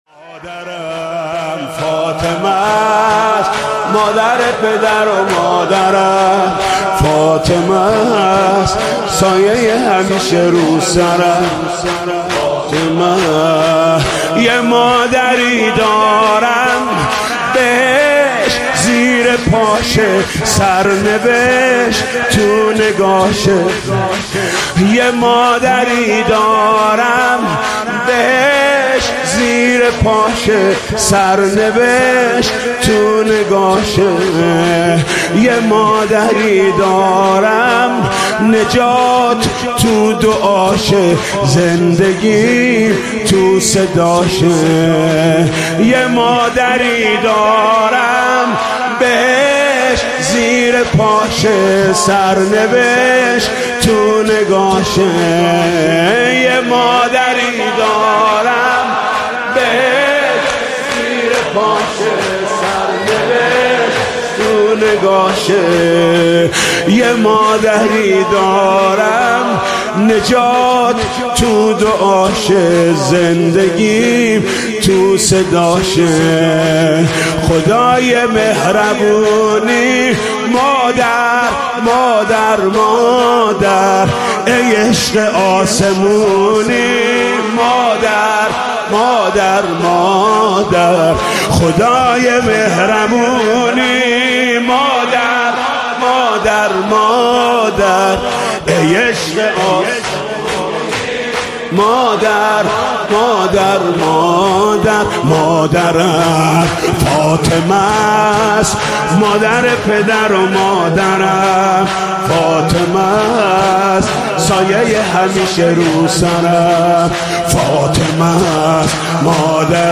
مداحی جدید حاج محمود کریمی شب سوم فاطمیه هیئت رایة العباس (ع) تهران جمعه 28 دی 1397